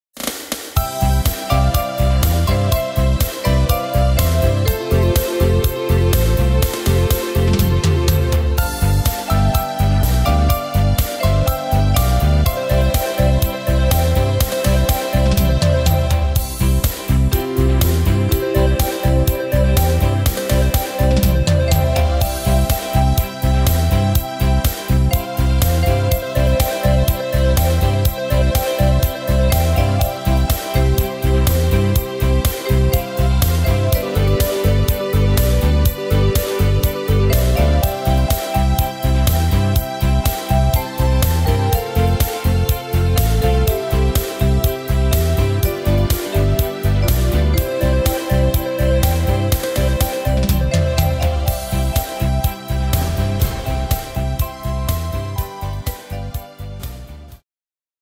Tempo: 123 / Tonart: C